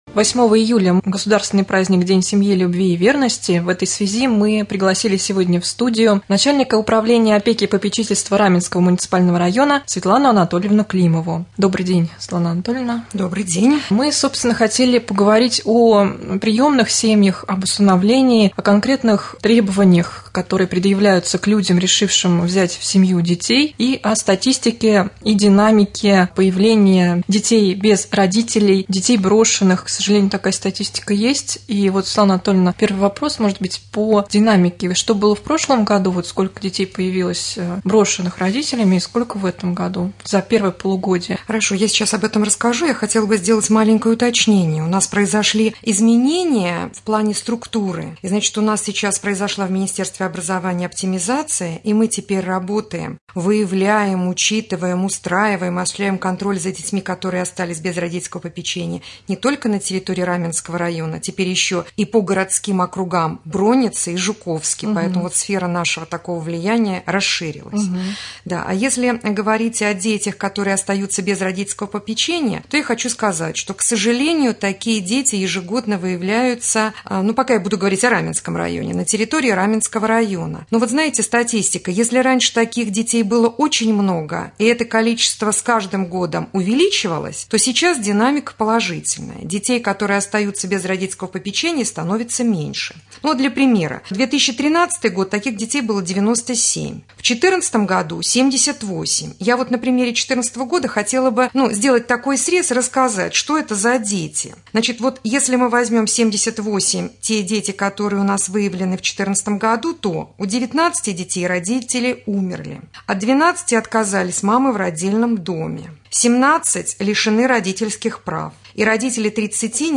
Рубрика «Актуальное интервью».